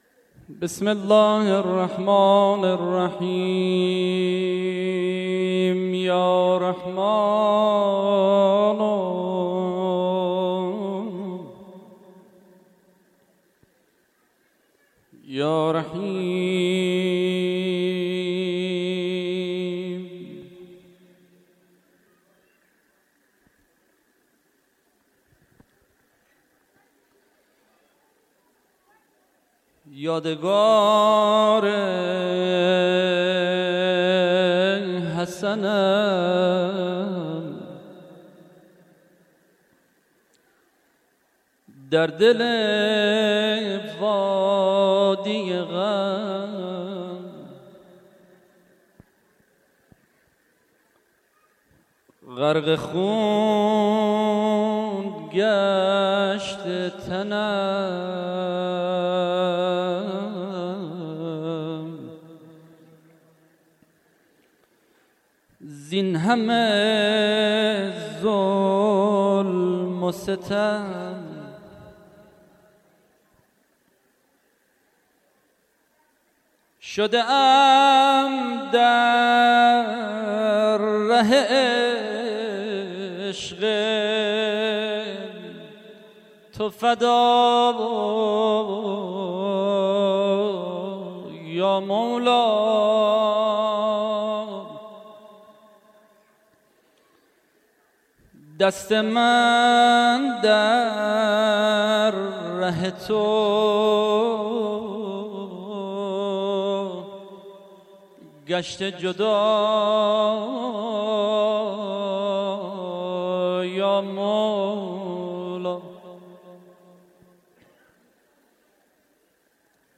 نوحه حضرت عبدالله بن الحسن(ع)